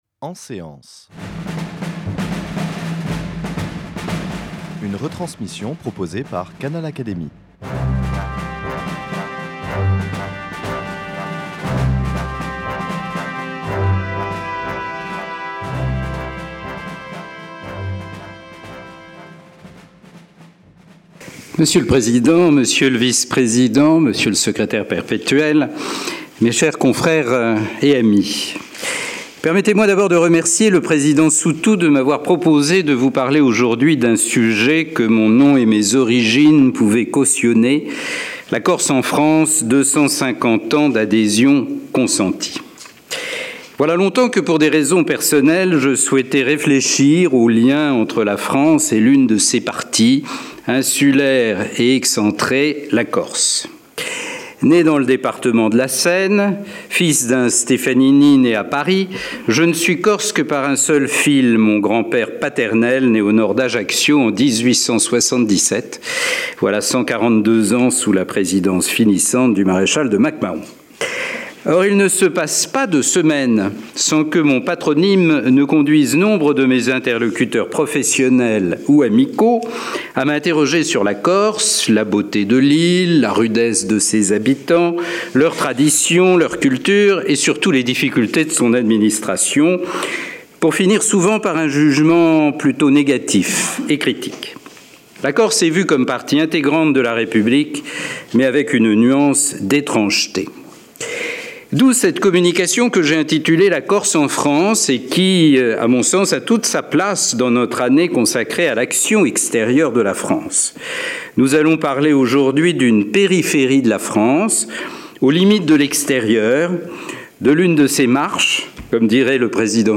À travers le cas de la Corse, composante singulière, mais à part entière, de la République, l’exposé entendait aussi interroger le rapport de la France avec ses périphéries.